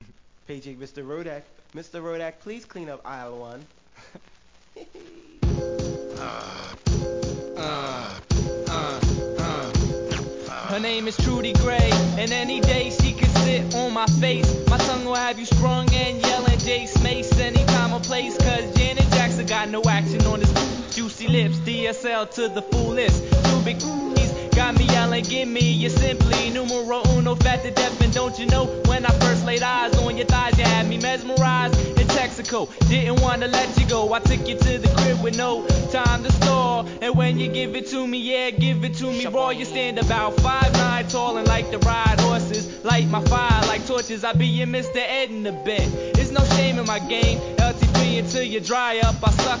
HIP HOP/R&B
極上のピアノLOOPと絶妙な抜き、1995年傑作アンダーグランド!!!